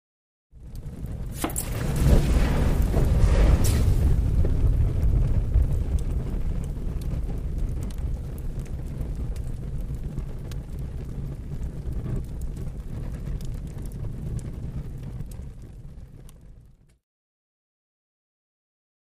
LighterFluidPoured HI027101
Lighter Fluid Poured on Fire